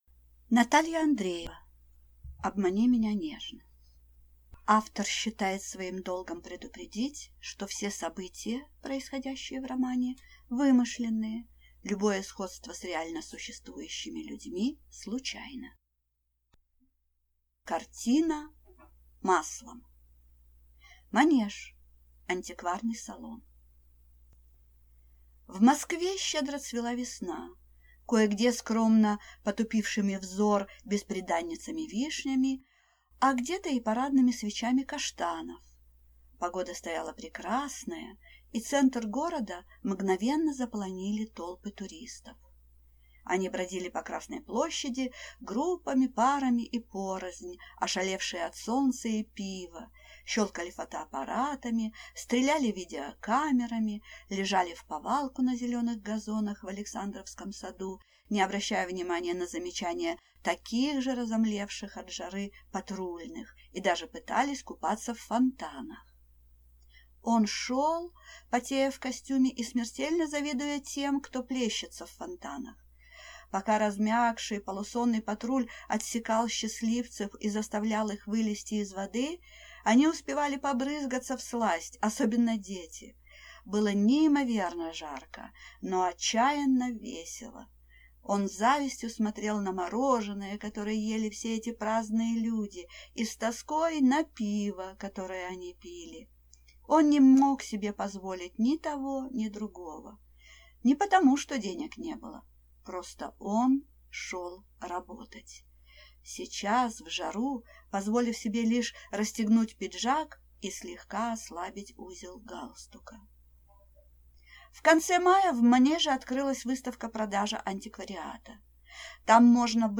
Аудиокнига Обмани меня нежно | Библиотека аудиокниг